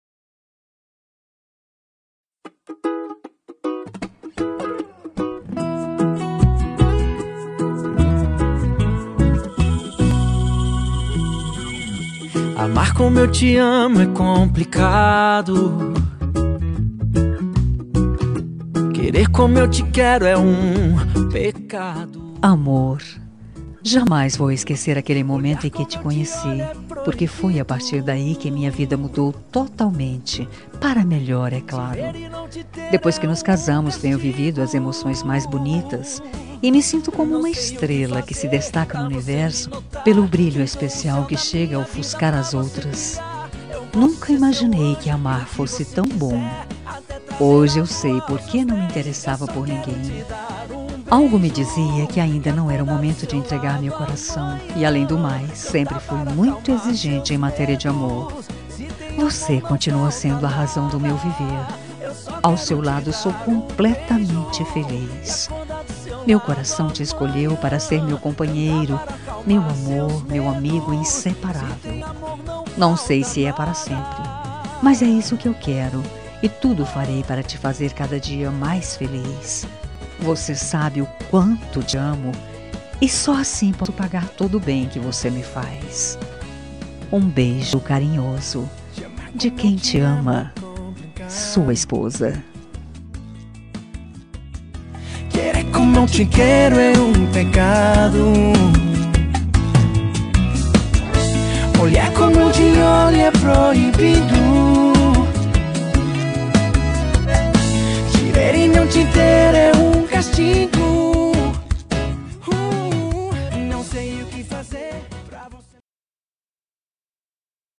Telemensagem Romântica para Marido – Voz Feminina – Cód: 201825